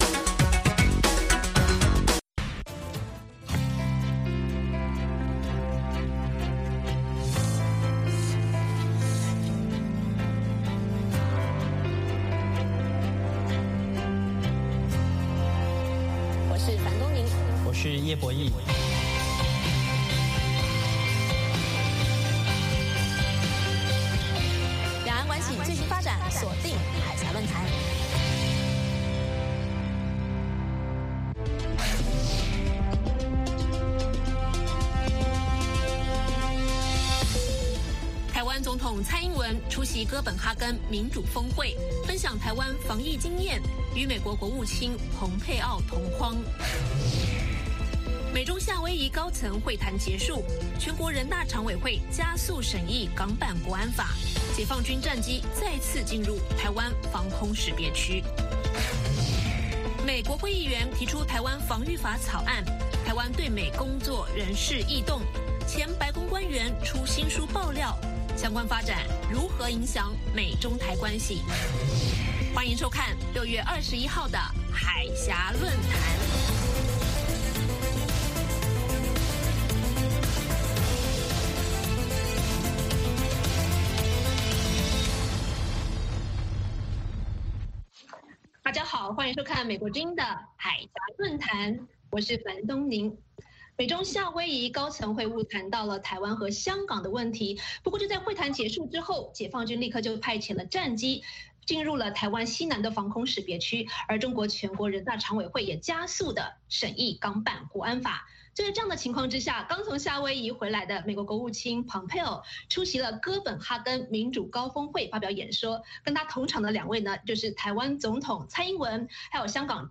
美国之音中文广播于北京时间每周一晚上8－9点重播《海峡论谈》节目。《海峡论谈》节目邀请华盛顿和台北专家学者现场讨论政治、经济等各种两岸最新热门话题。